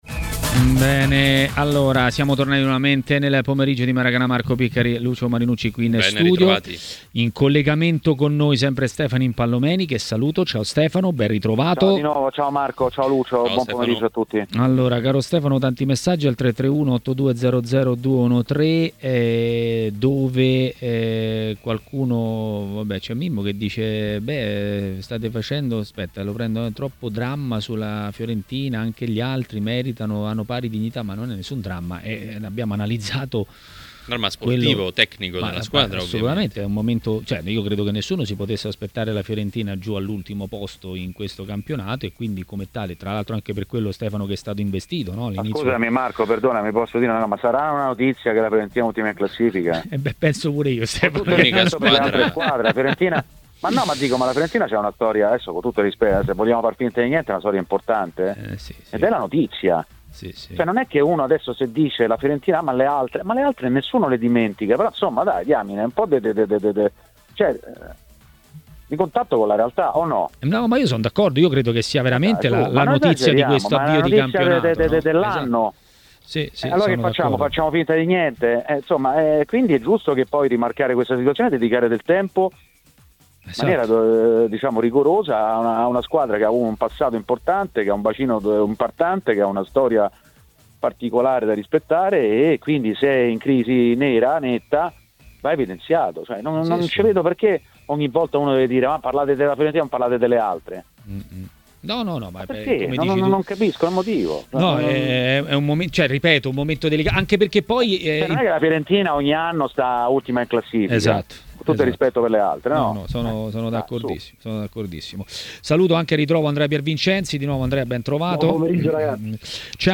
Ospite di Maracanà, nel pomeriggio di TMW Radio